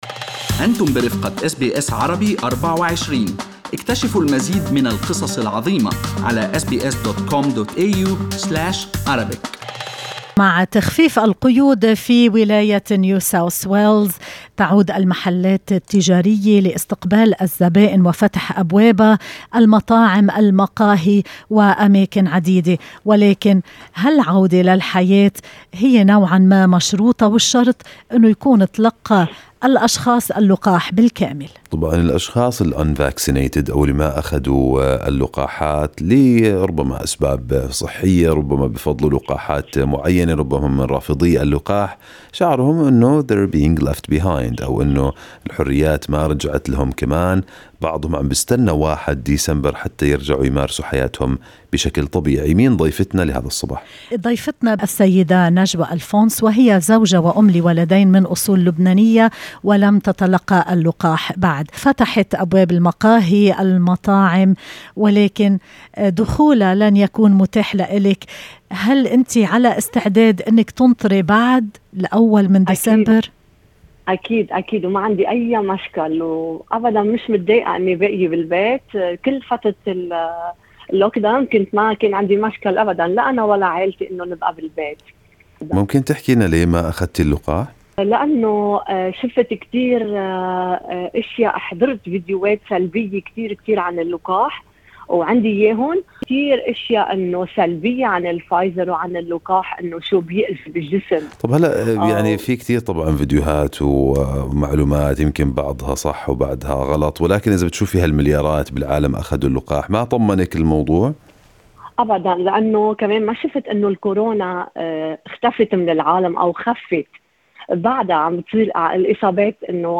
"أفضل الانتظار": سيدة عربية تتحدث عن مخاوفها من لقاح كورونا